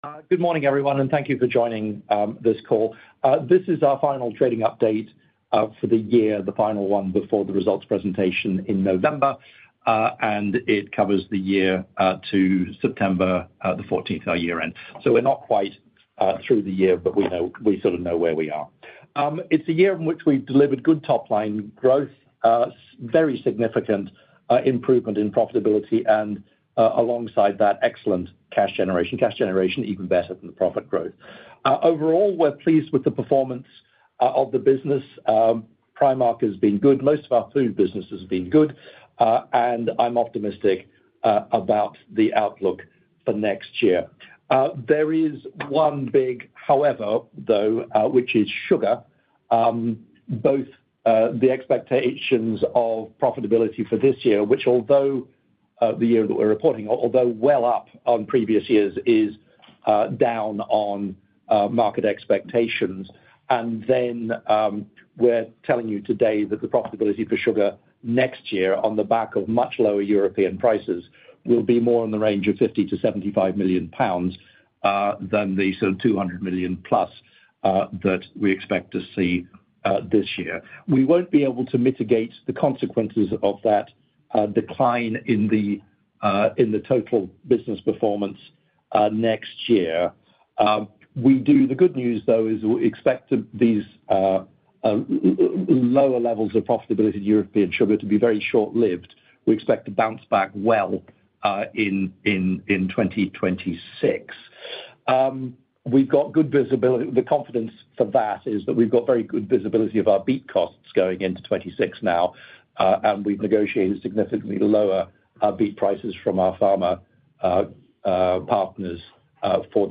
abf-september24-trading-update-call.mp3.downloadasset.mp3